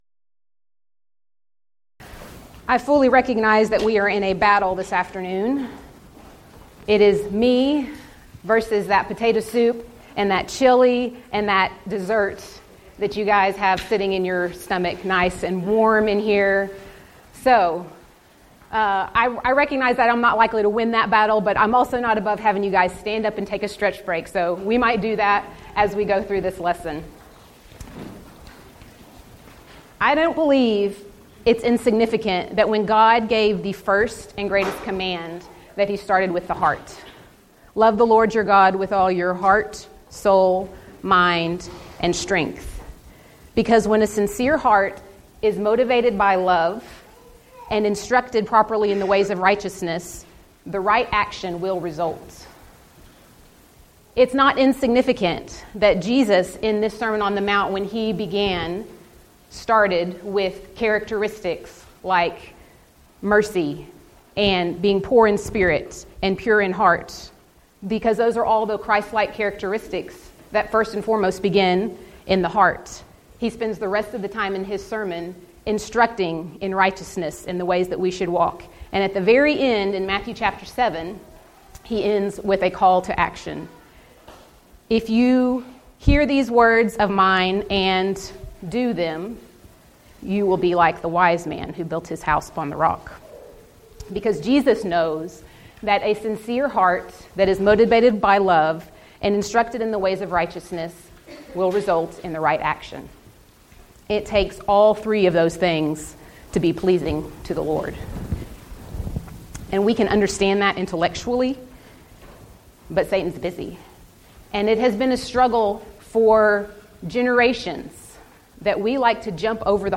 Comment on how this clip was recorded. Event: 10th Annual Texas Ladies in Christ Retreat